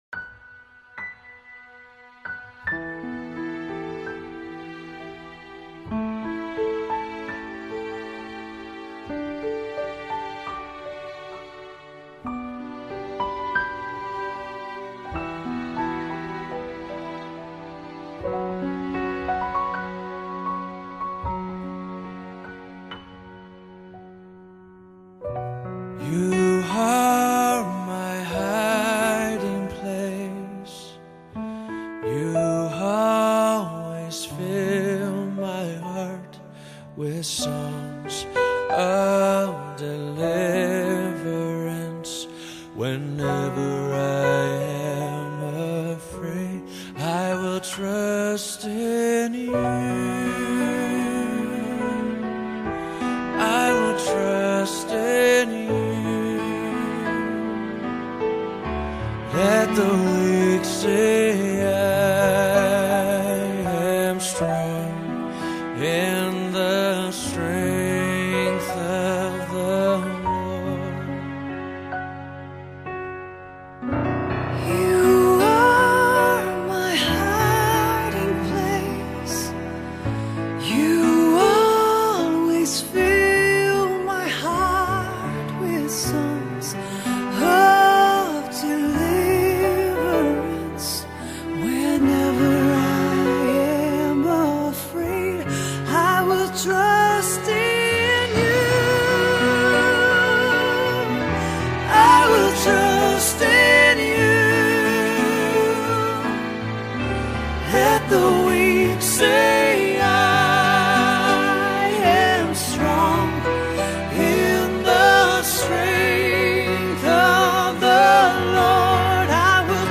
5396 просмотров 3058 прослушиваний 510 скачиваний BPM: 79